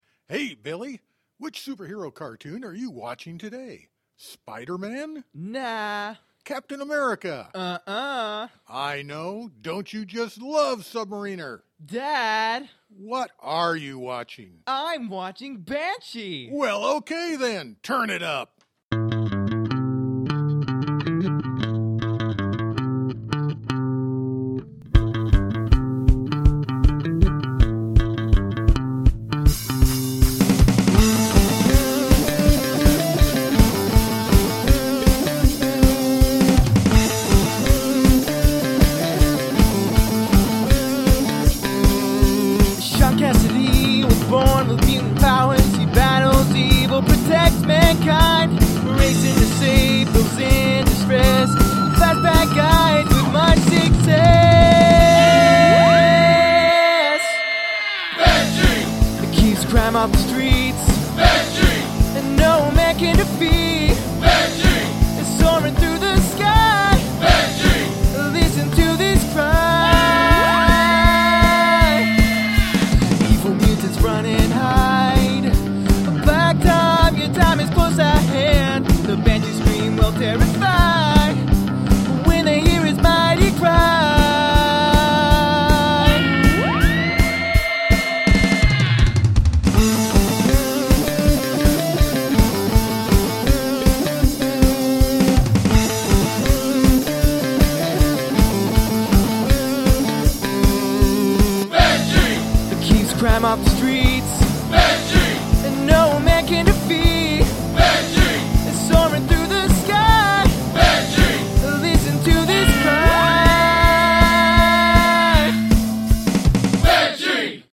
Must use the voice or mouth sounds as an instrument (other than vocals)
(Theme song for Banshee, former member of the X-men)
The lead vocalist has a couple of "pitchy" moments that pretty much sealed your fate.